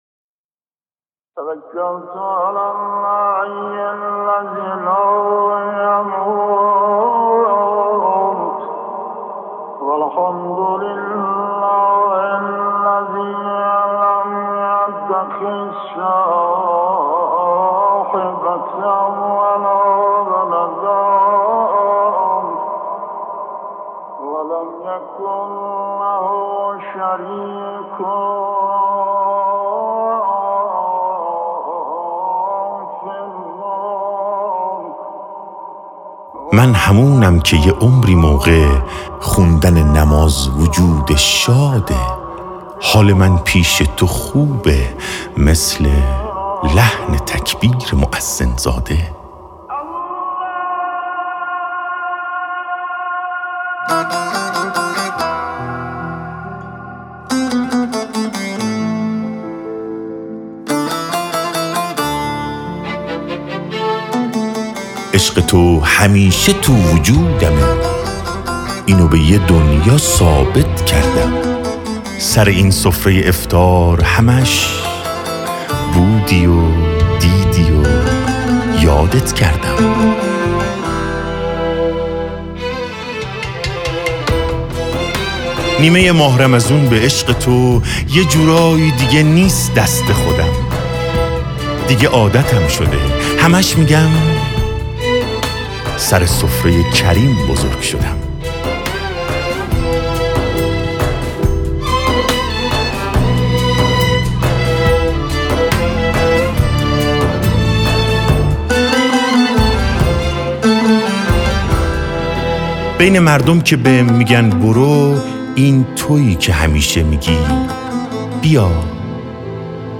نماهنگ